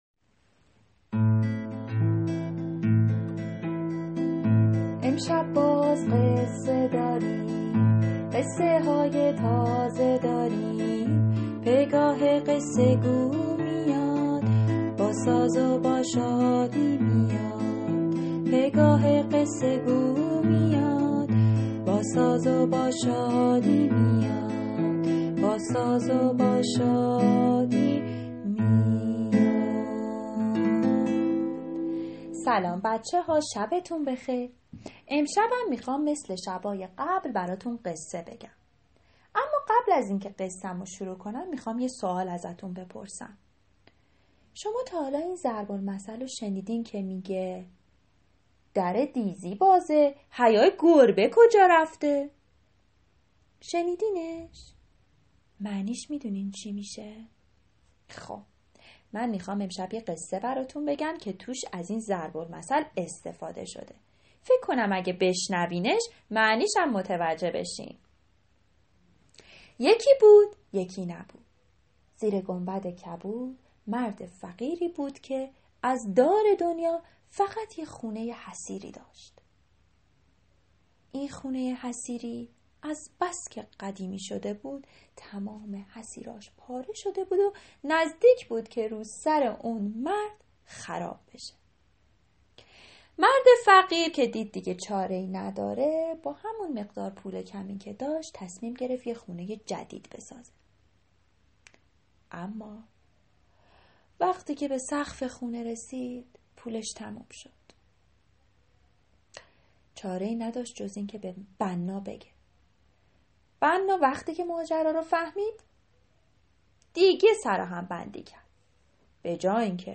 قصه صوتی کودکان دیدگاه شما 934 بازدید